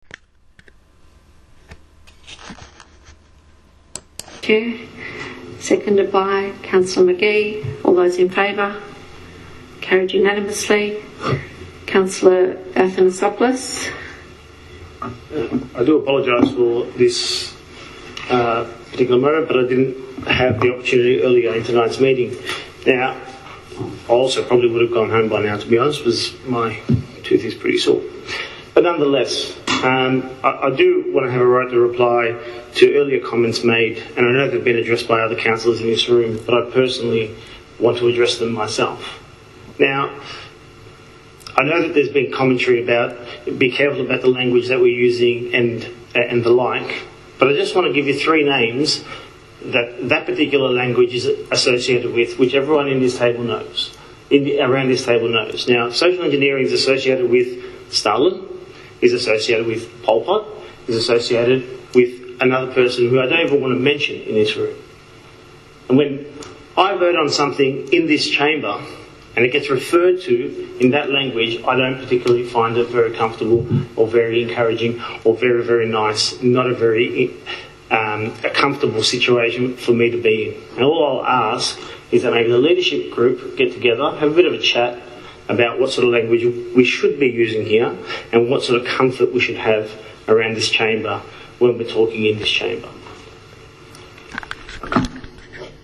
Last night’s council meeting showed the first public sign that maybe things aren’t as hunky dory within this councillor group as they would like us to believe.